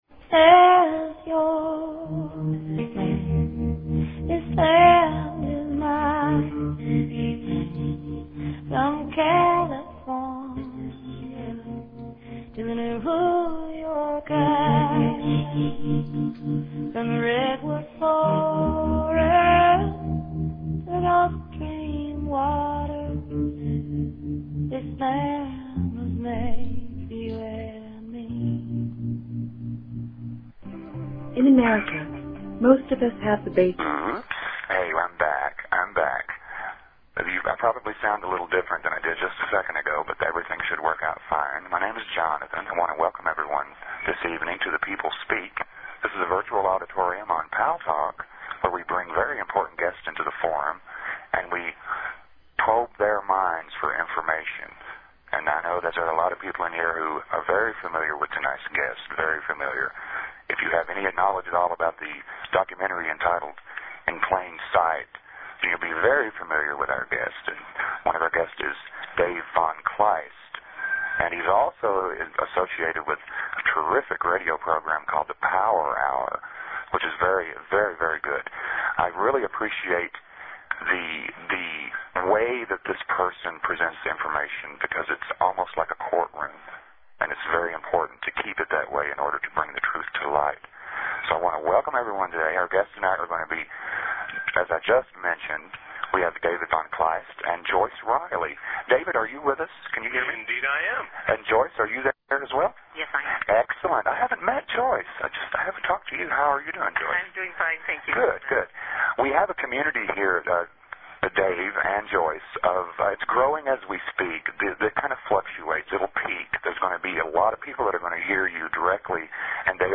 The show features a guest interview from any number of realms of interest (entertainment, science, philosophy, healing, spirituality, activism, politics, literature, etc.).
The radio show name, The People Speak, is based on the idea of allowing our audience - the People - a chance to interact with the guests during the hour, and we take phone or text questions from them during the interview.